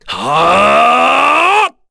Dakaris-Vox_Casting4_kr.wav